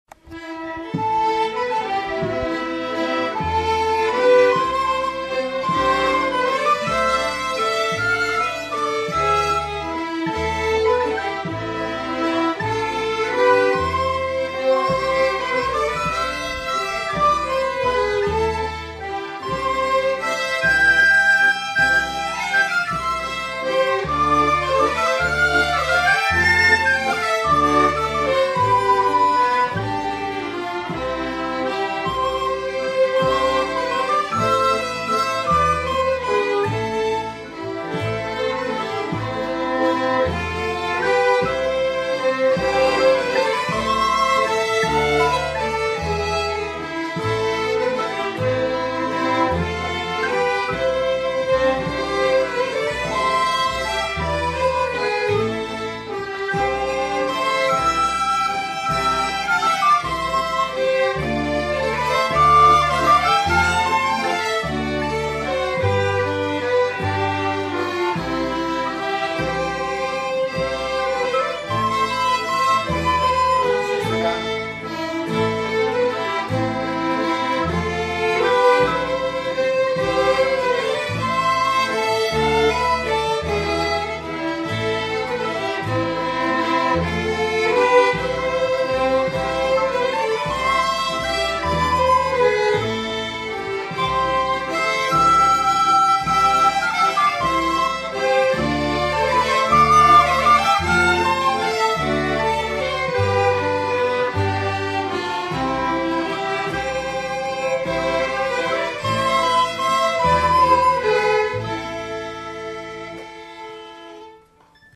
Musikken p� �lejren p� Ly�